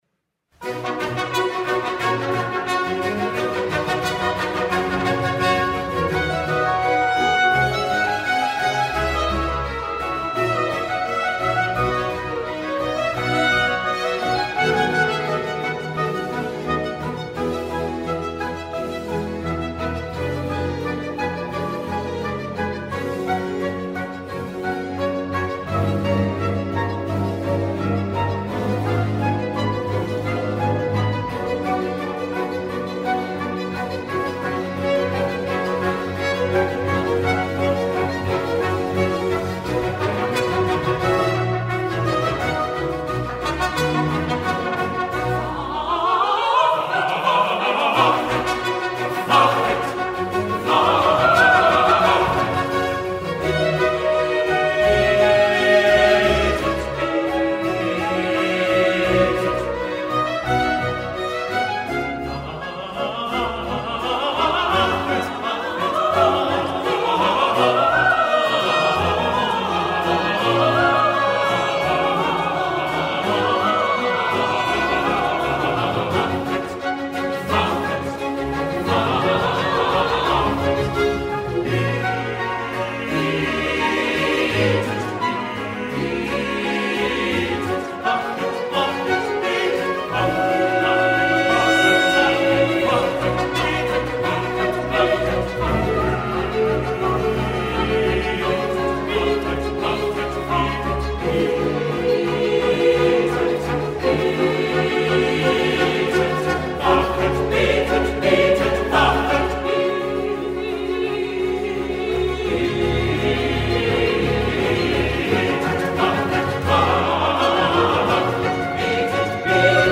J.S.-Bach-Cantata-BWV-70-Wachet-Betet-Betet-Wachet-1-Chorus.mp3